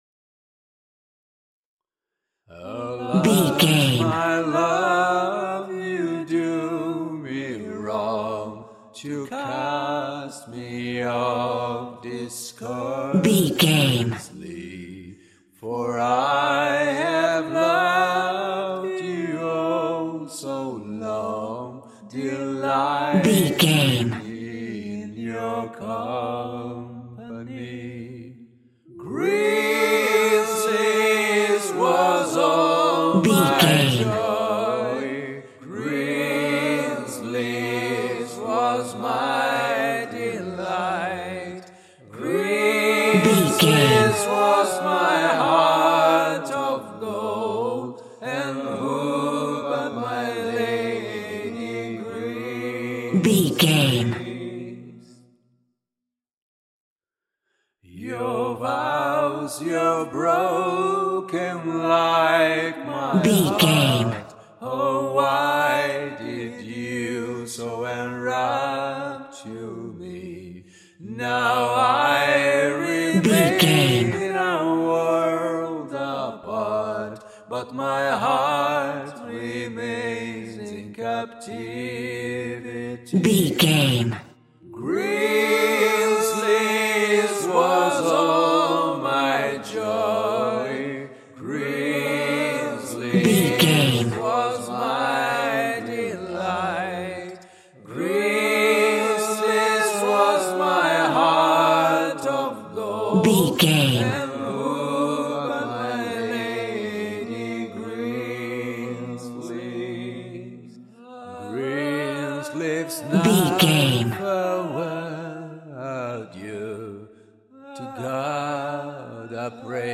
Ionian/Major
fun
groovy
inspirational